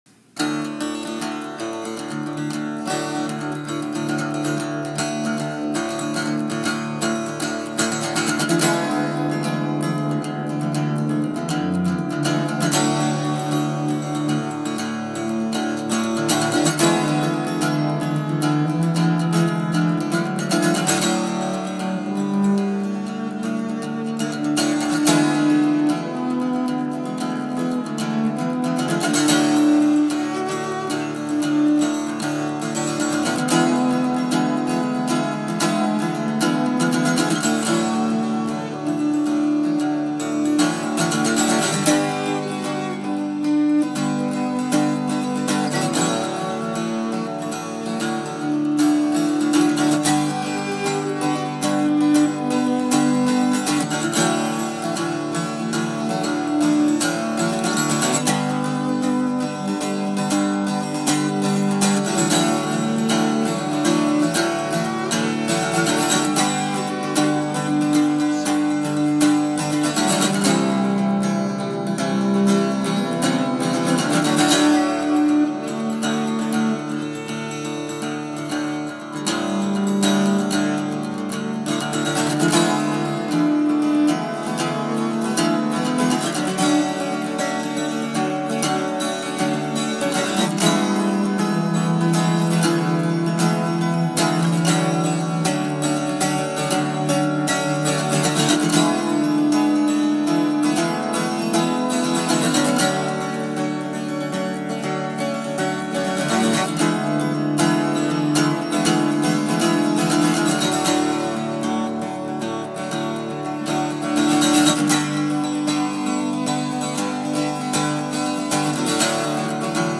vocals, cello, keyboards, acoustic bass, guitar
guitars, cuatro/charango, Irish bouzouki, vihuela, saxophone
Guest pianist